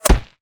bullet_impact_dirt_08.wav